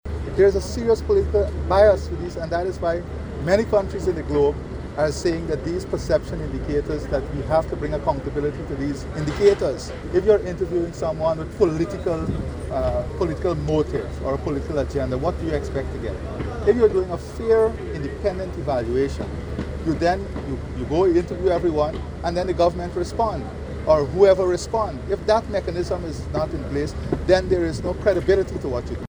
Responding to questions from reporters about Guyana’s ranking of 39 in TI’s corruption assessment, President Ali questioned the impartiality of the report, asserting that it lacked credibility and might have been influenced by political factors.